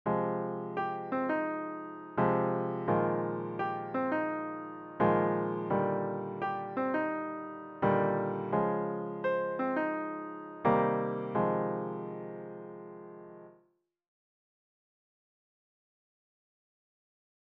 テーマの4小節のコードを使ってイントロを作りました。
左手でコード、右手でソドミトロディーを繰り返して4小節目でメロディーを変えます